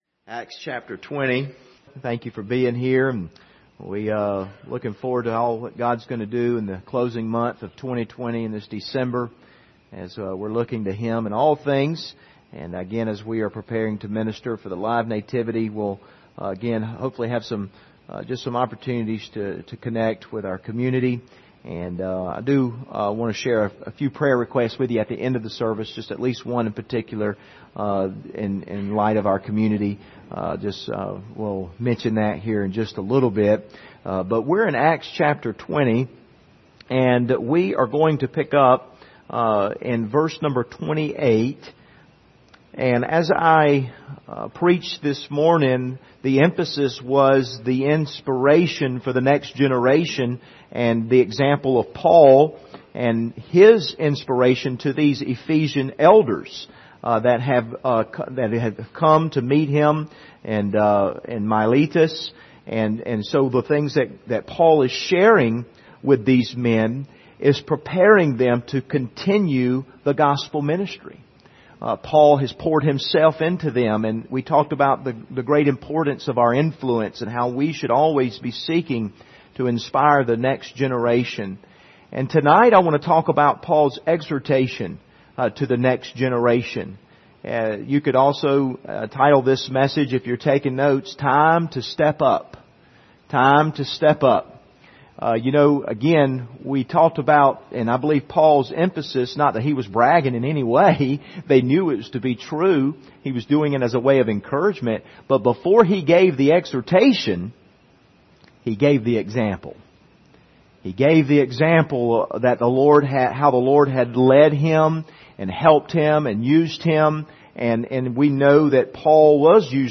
Passage: Acts 20:25-38 Service Type: Sunday Evening